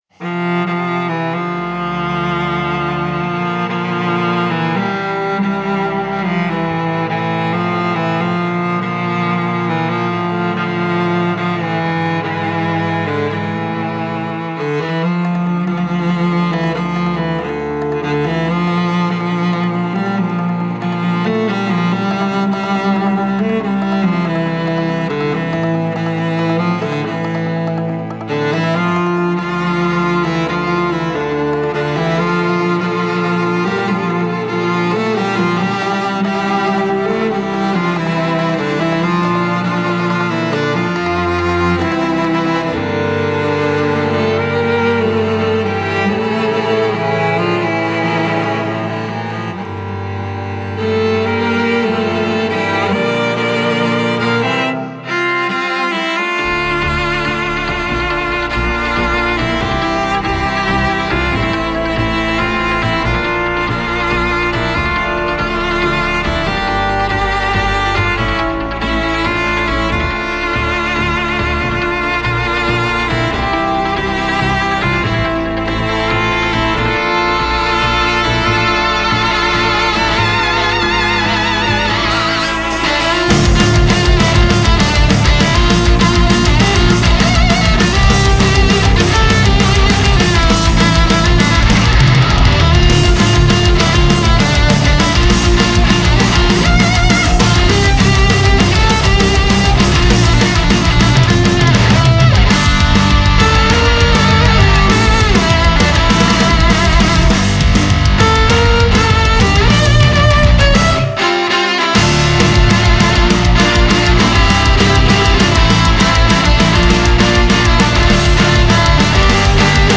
progressive metal Rock Symphonic metal